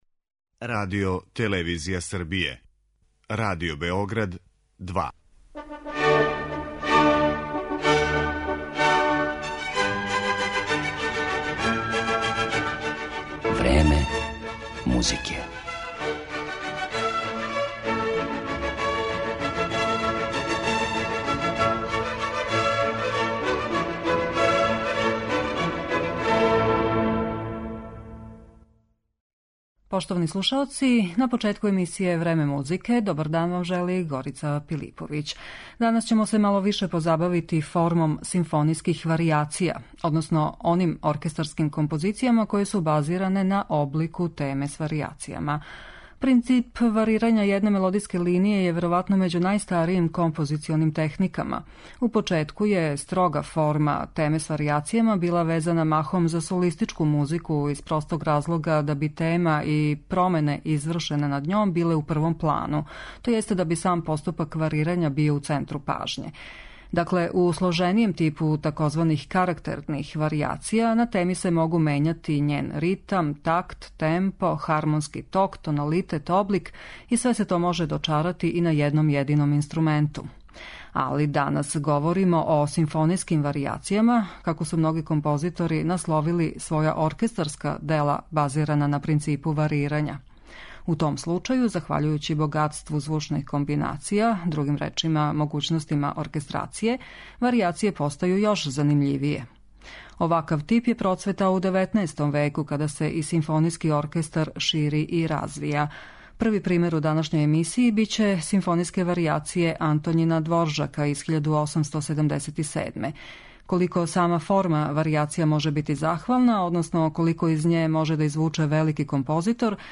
Симфонијске варијације су врста оркестарске композиције базиране на стандардном облику теме с варијацијама.